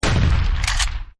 shot.mp3